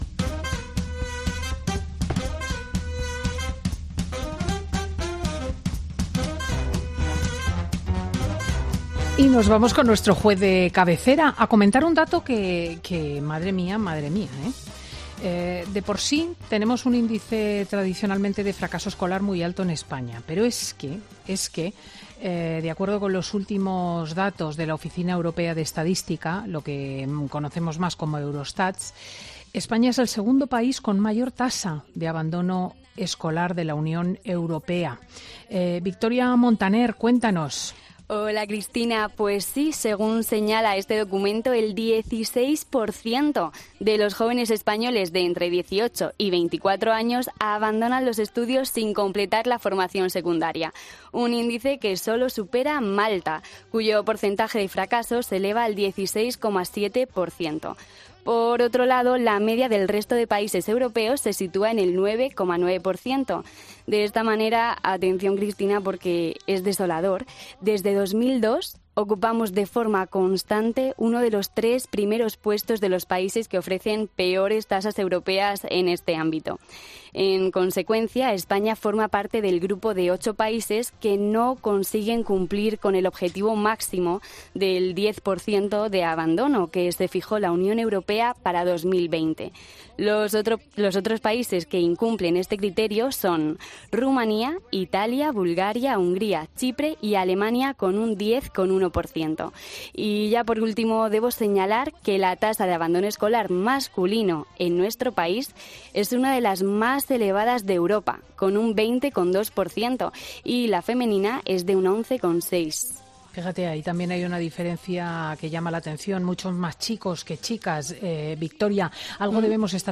El juez de menores, Emilio Calatayud, opina emocionado en Fin de Semana con Cristina sobre el abandono escolar como detonante de la delincuencia juvenil.